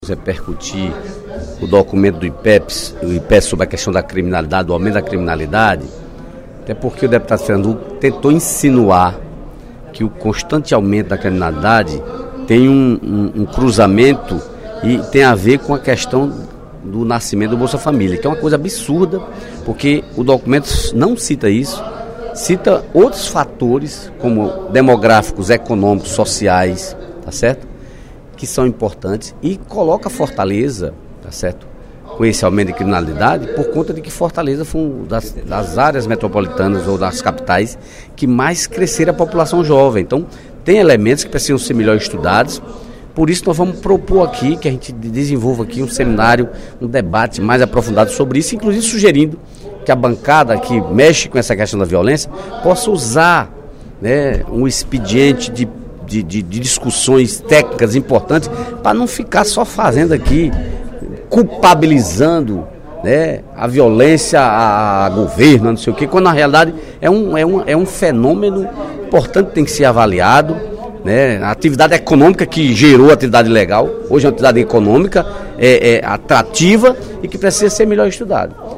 O deputado Dedé Teixeira (PT) contestou, nesta quarta-feira (03/04), durante o primeiro expediente da sessão plenária, a fala do deputado Fernando Hugo (PSDB) que, com base em documento do Instituto de Pesquisa e Estratégia Econômica do Ceará (Ipece), relacionou o benefício do Bolsa Família ao aumento da violência no Ceará. De acordo com Dedé Teixeira, o relatório do Ipece não faz essa relação e seria preciso um aprofundamento na análise dos dados para poder fazer tal afirmação.